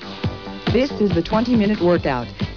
Snd.  - Intro voice "This is the :20 Minute Workout".   [ 28 KB ]